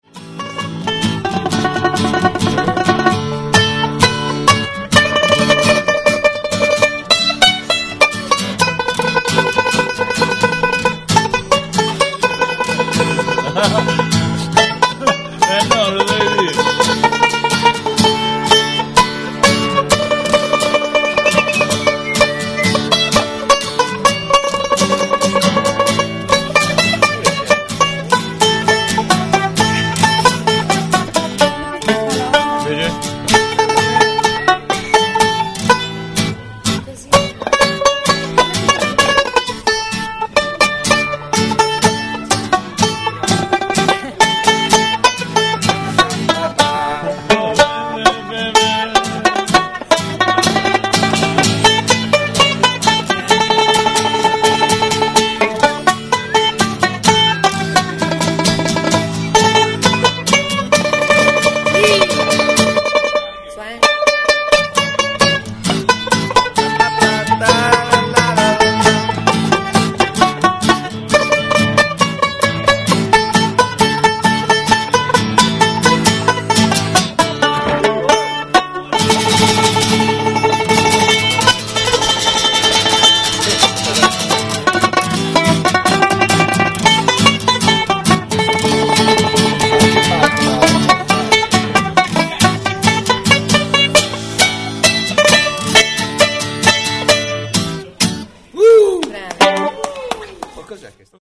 19th April 2003 - Easter in Barga Vecchia
small MP3 file to get the feel of simple direct music being played just for fun in the Piazza.